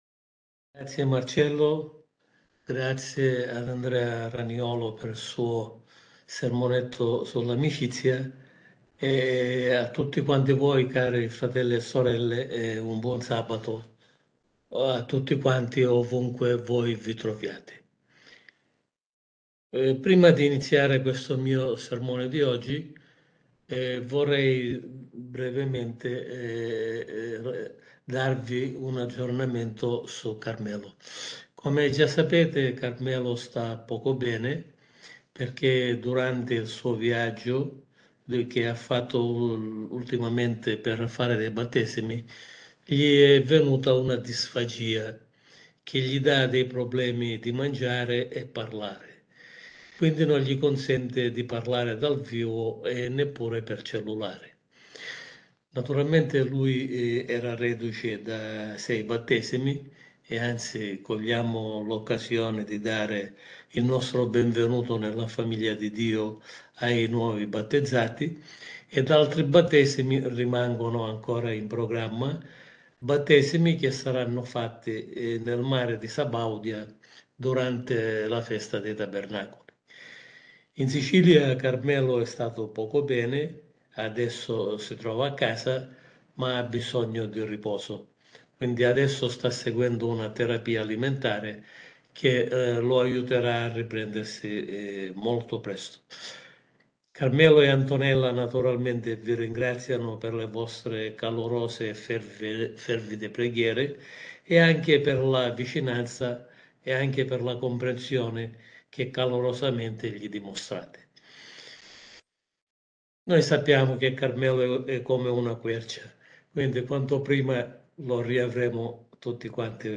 Combattiamo l’ira – Sermone pastorale